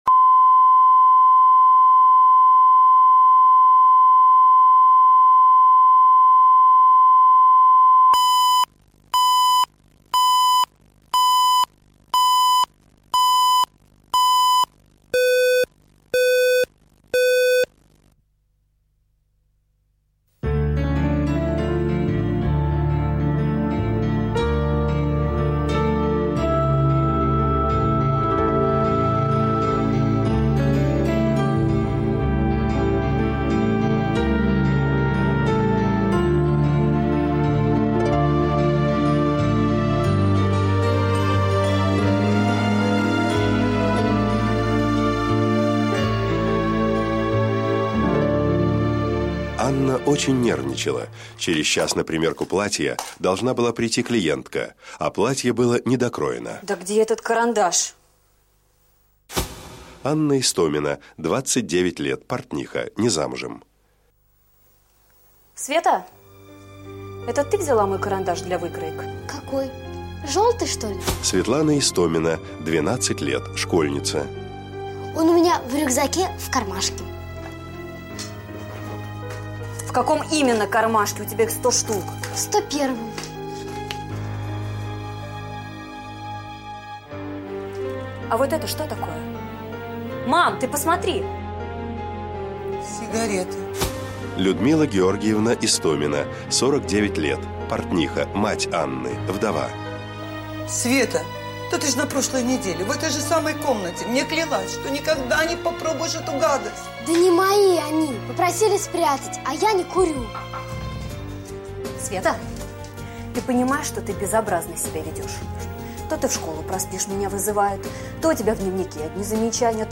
Aудиокнига Тайна рождения Автор Александр Левин.